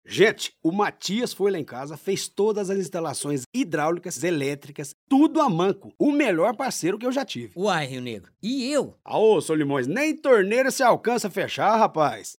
Com a ajuda da equipe da produção da Hungry Man, acabamos montando uma cabine com mantas acústicas em cima de uma mesa no camarim dos artistas.
Para Rio Negro usamos um Shure SM7B, um microfone dinâmico que capta menos som de ambiente que um microfone condensador estilo Neumann TLM 103. Para Solimões usamos um Sennheiser MKH-416, um condensador frequentemente utilizado como boom em sets de filmagens.